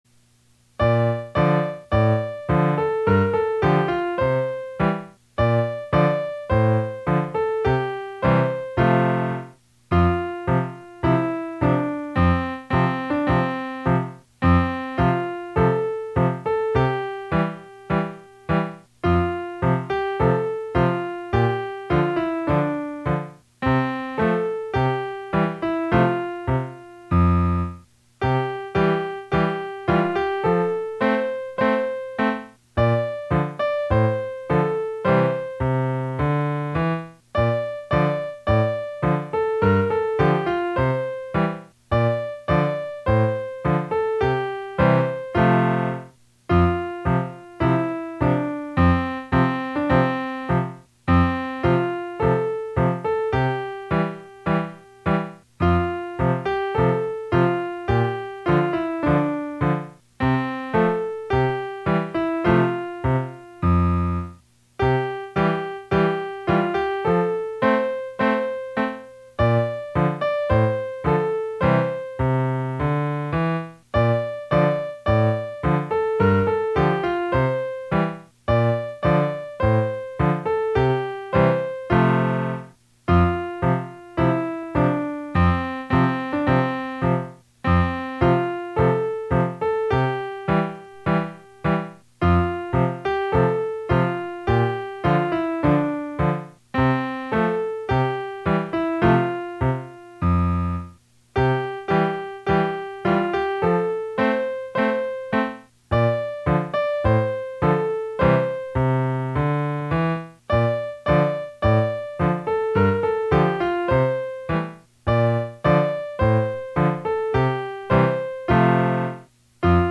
校歌
koukautanasi.mp3